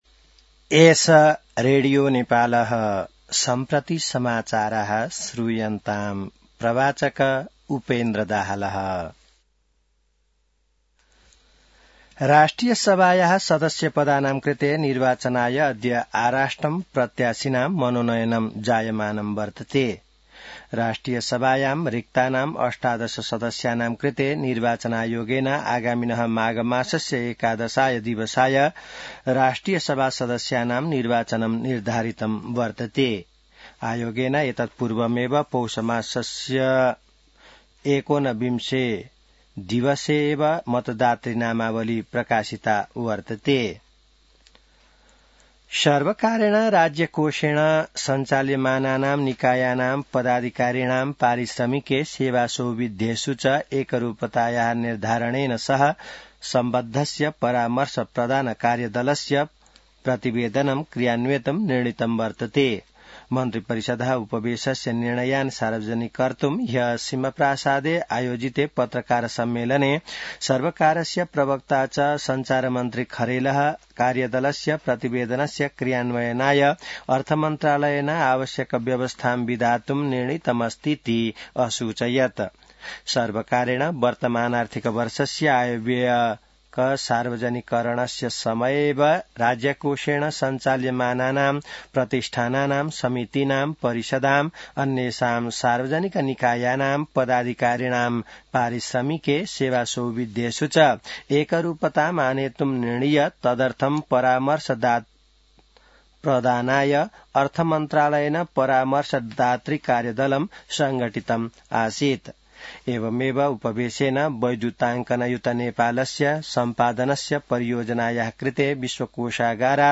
संस्कृत समाचार : २३ पुष , २०८२